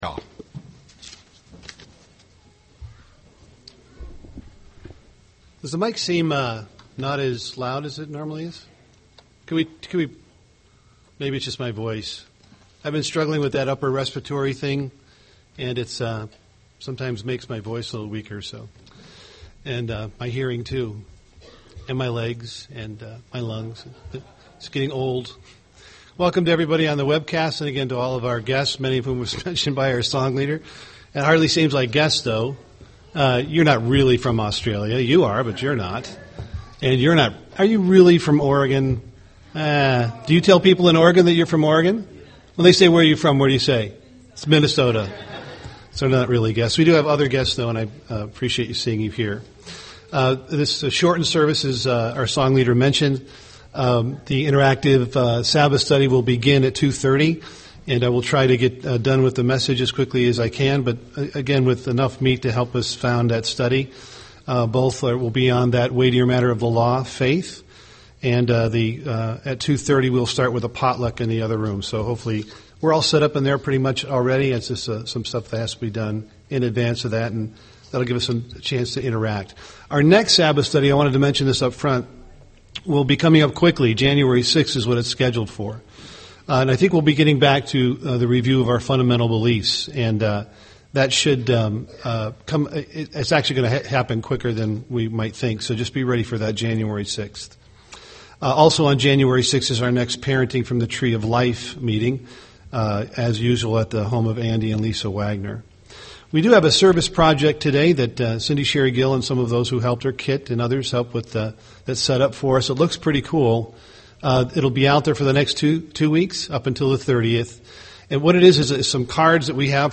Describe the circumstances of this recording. Given in Twin Cities, MN